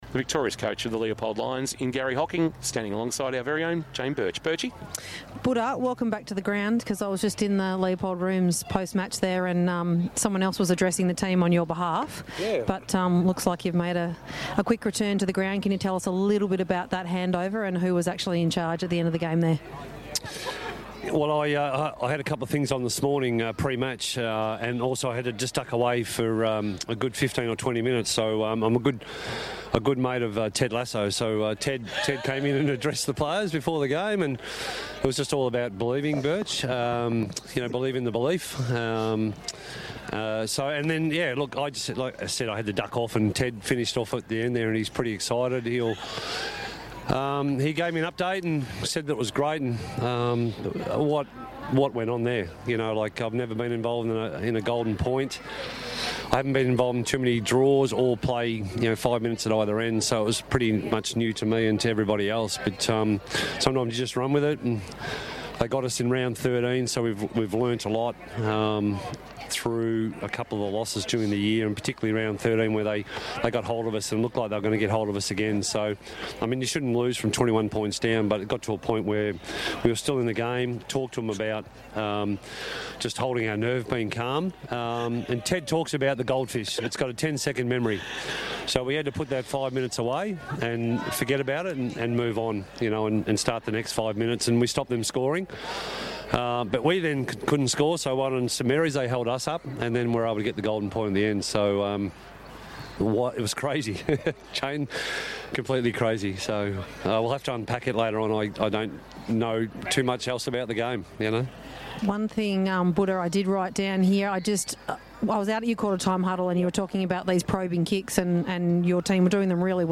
2024 - GFNL - Qualifying Final - St Mary's vs. Leopold: Post-match interview - Garry Hocking (Leopold coach)